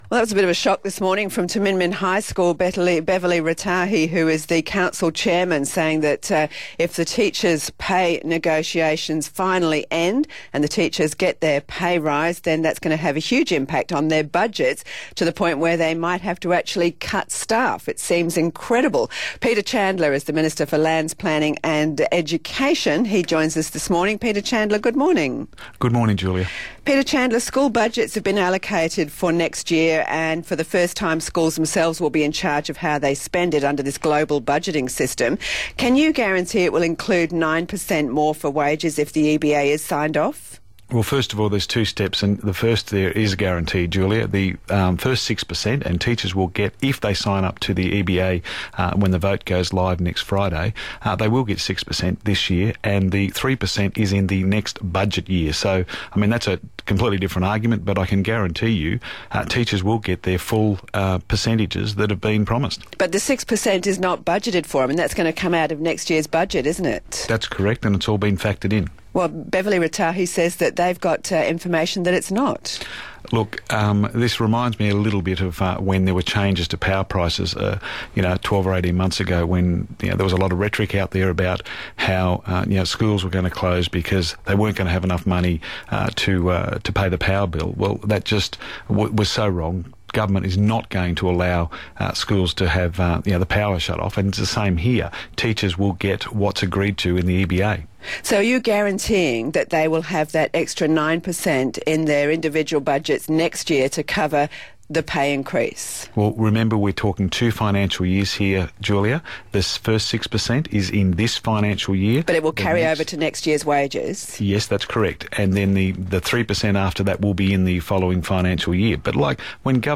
Media and interviews (opens in audio player)